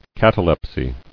[cat·a·lep·sy]